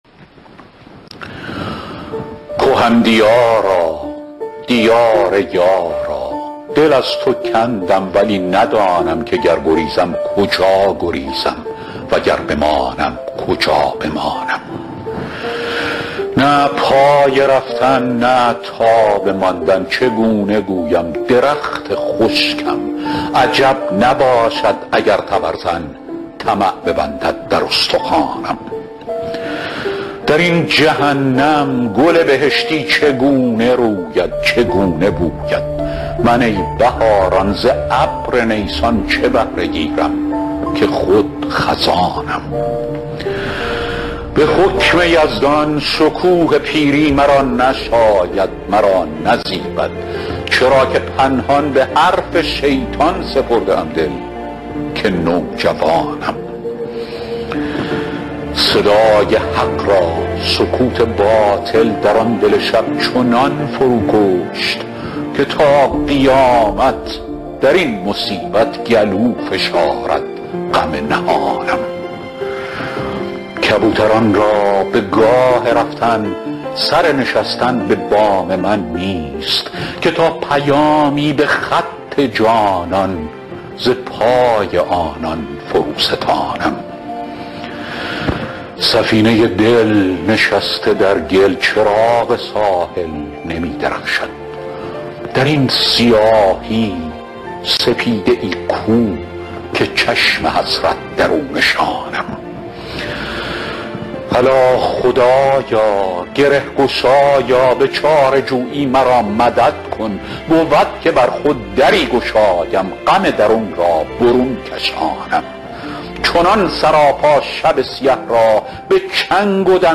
دانلود دکلمه کهن دیارا با صدای فریدون فرح اندوز
اطلاعات دکلمه
گوینده :   [فریدون فرح اندوز]